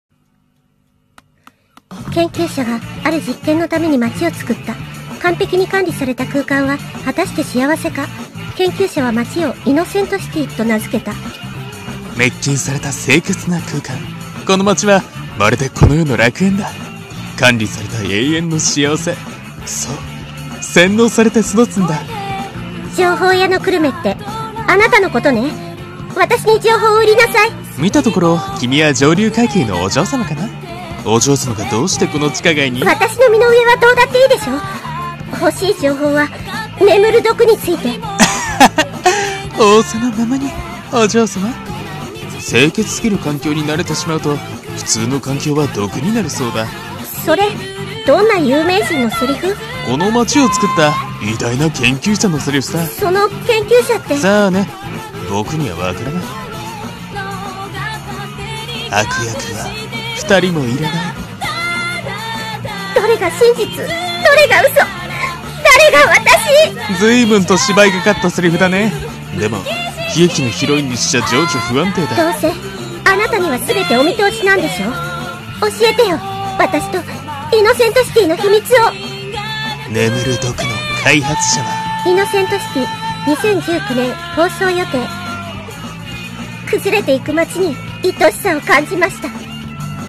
声劇台本